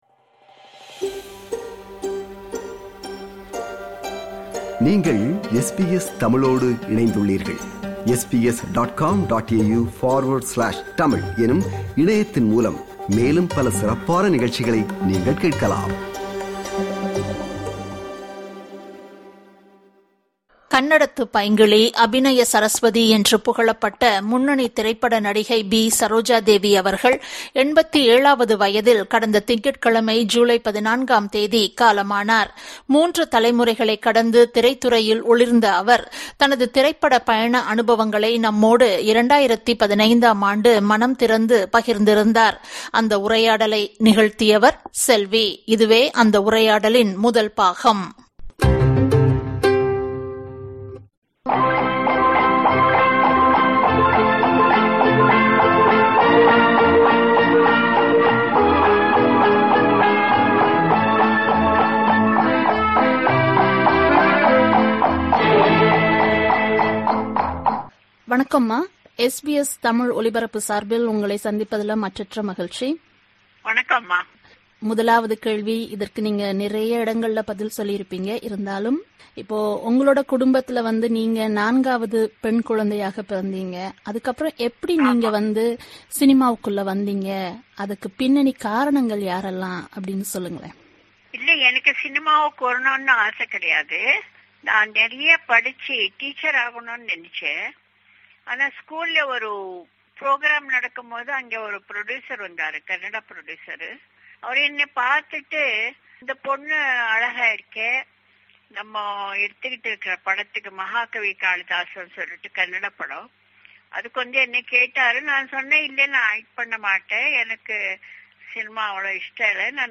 நடிகை சரோஜாதேவி அவர்களுடனான செவ்வியின் முதல் பாகம்.